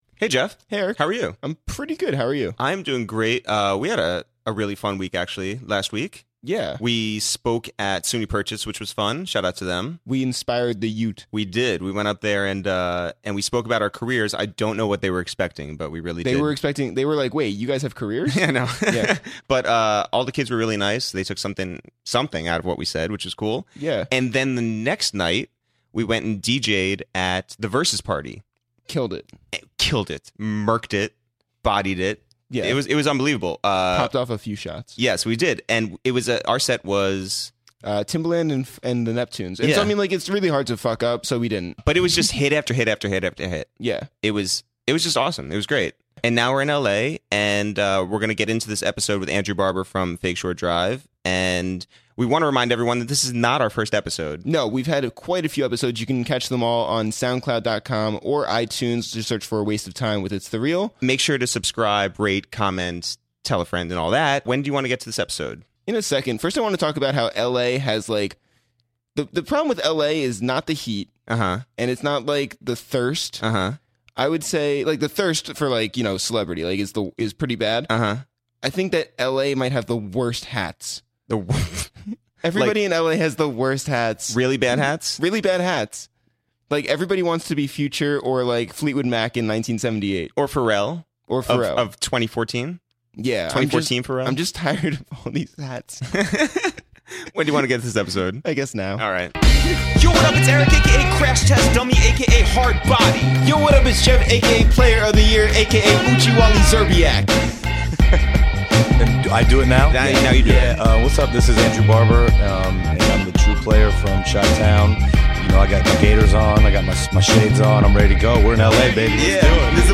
to the studio in Los Angeles, while we're all in town for The Grammys.